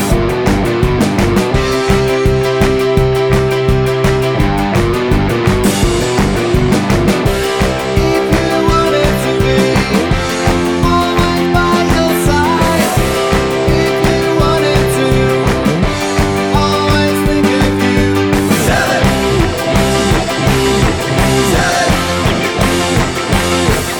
No Backing Vocals Glam Rock 3:14 Buy £1.50